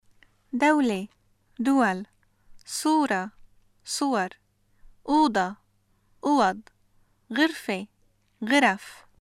シリアのアラビア語 文法 名詞の複数形：例文
[dawla (dəwal), Suura (Səwar), ʔuuDa (ʔəwaD), ġərfe (ġəraf)]